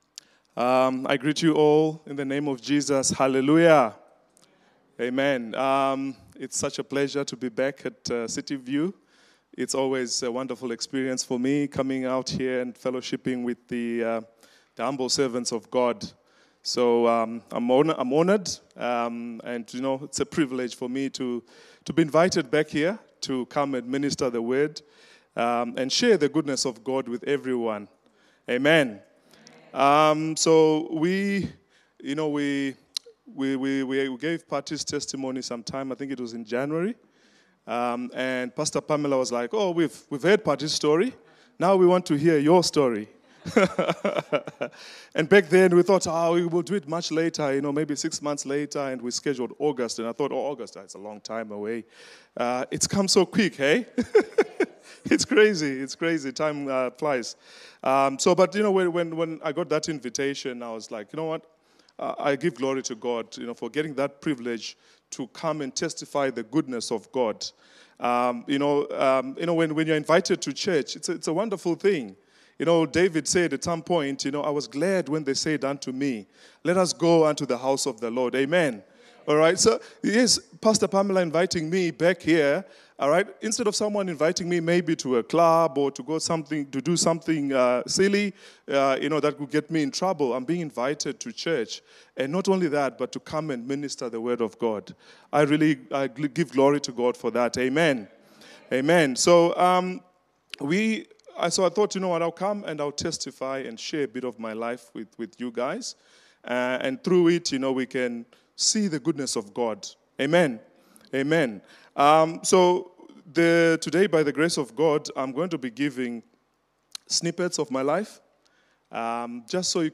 The key to an enduring, successful and unshaken Christian life is having your own personal encounter with God. Here is my personal testimony.